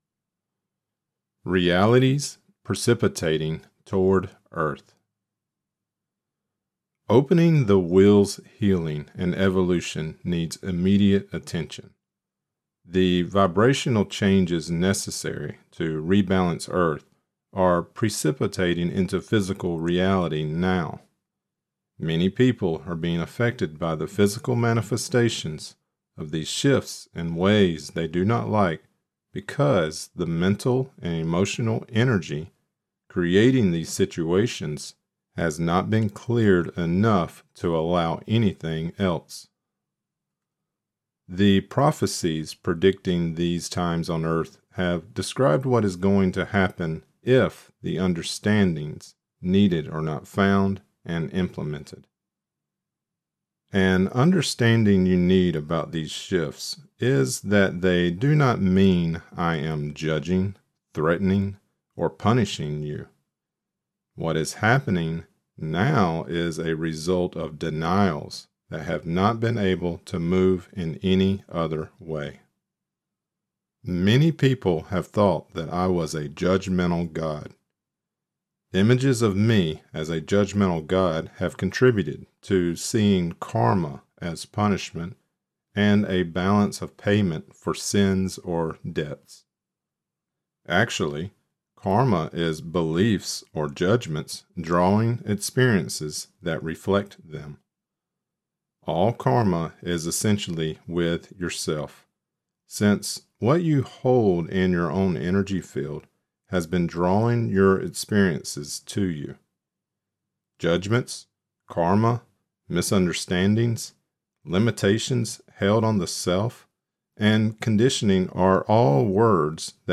This is part 10 of the Right Use of Will and this lecture includes: Realities Precipitating Toward Earth.